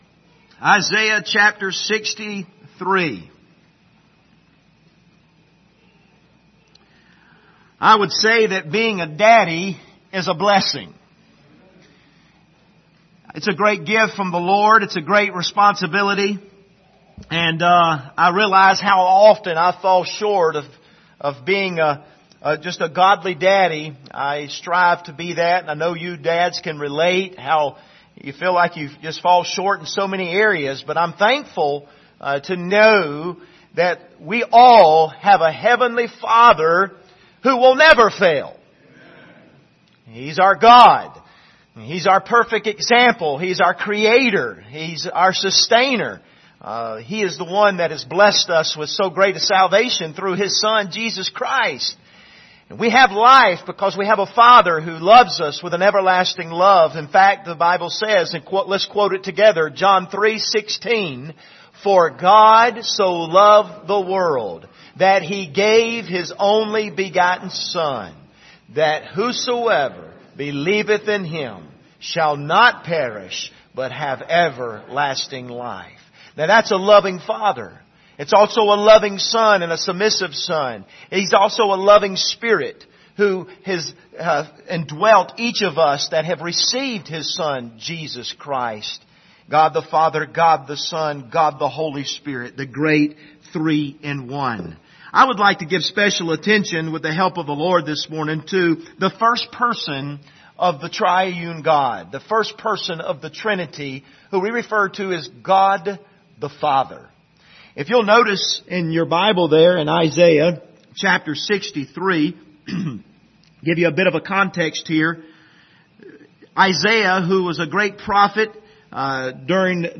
Isaiah 63:7-65:2 Service Type: Sunday Morning Topics